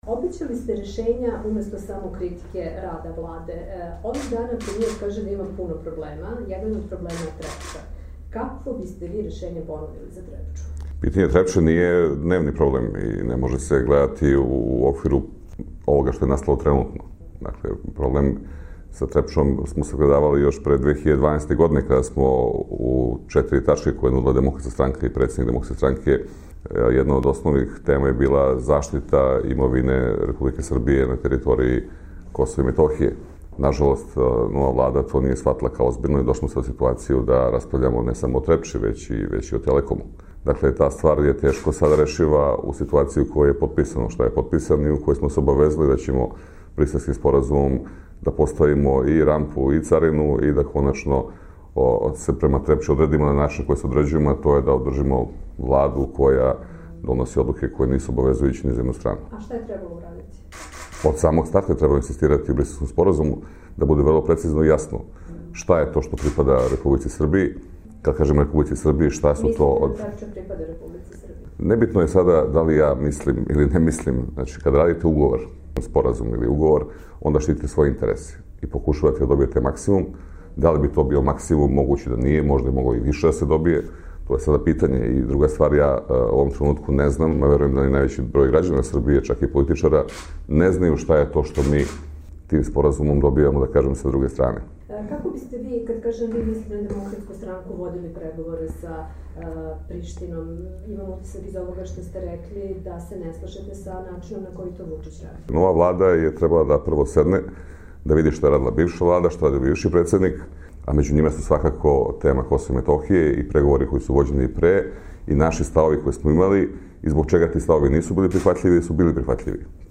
Intervju nedelje: Dragan Šutanovac